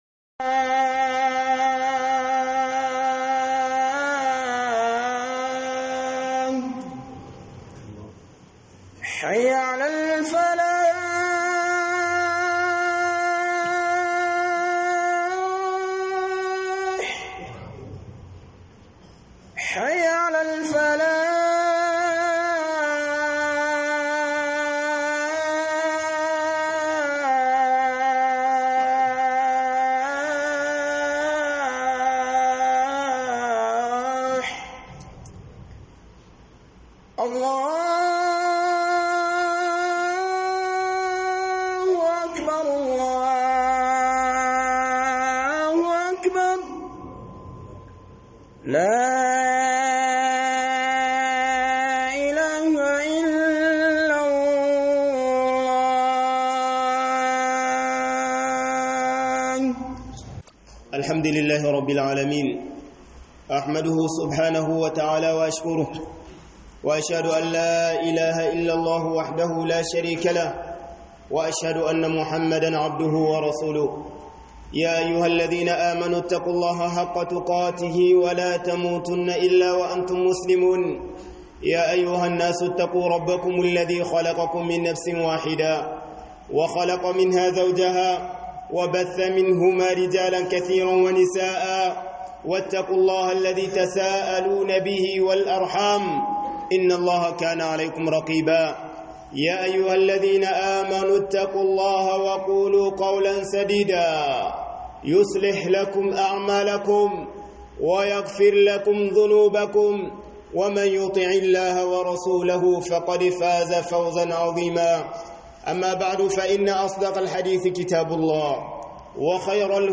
ADDININ KA SHINE IZZAR KA - Huduba